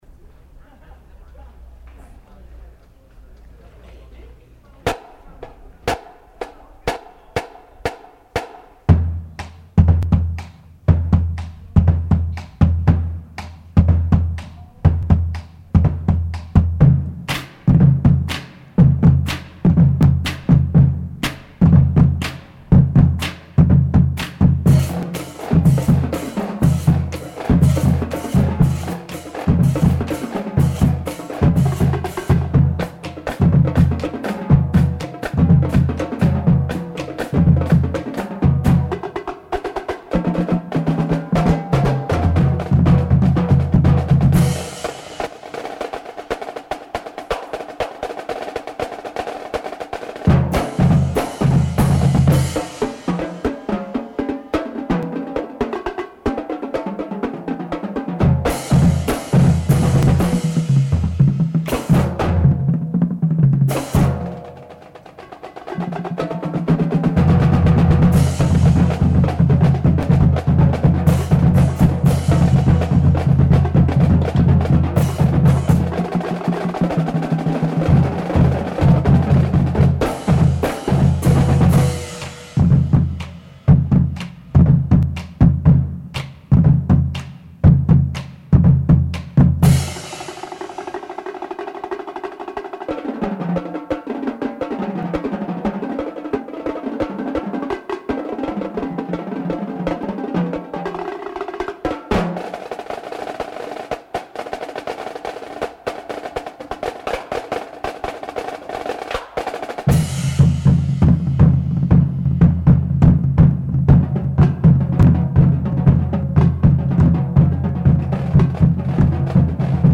「2002年横浜開港記念みなと祭　第50回ザよこはまパレード（国際仮装行列）」で使用したドラム・ソロです。
drum_solo02_160.mp3